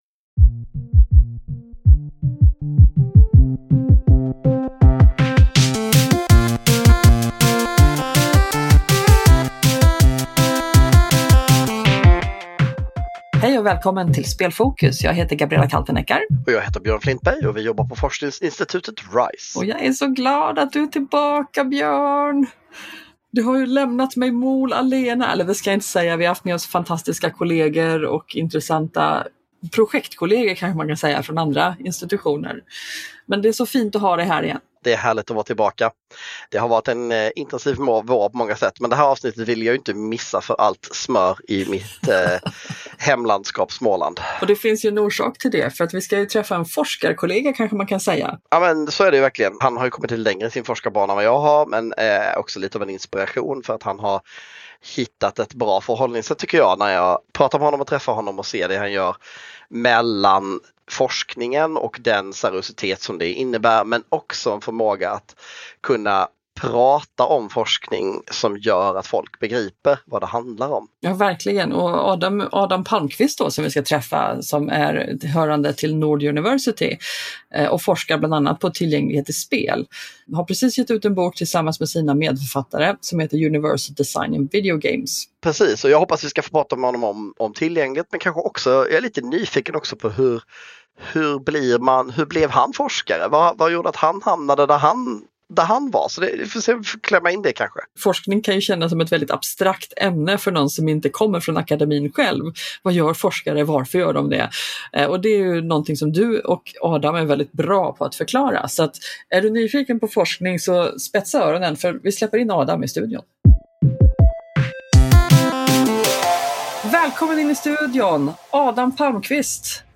Häng med på ett tillgängligt akademiskt samtal